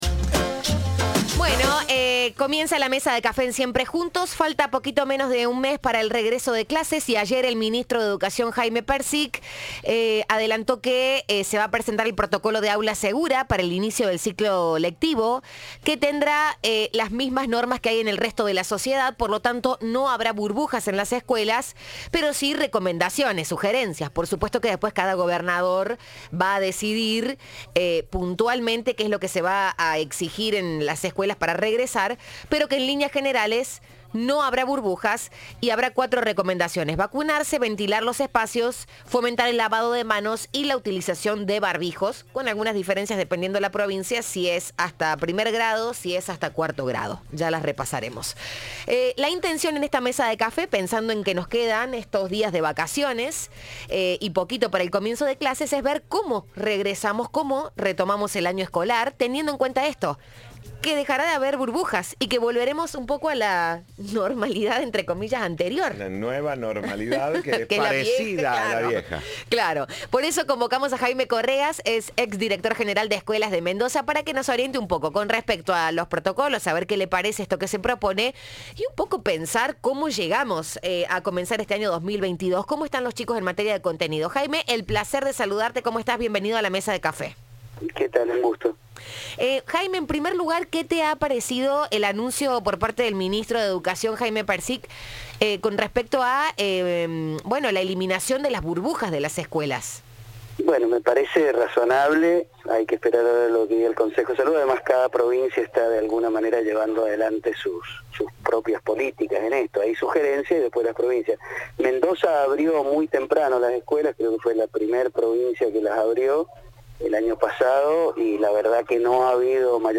Entrevista de Siempre Juntos.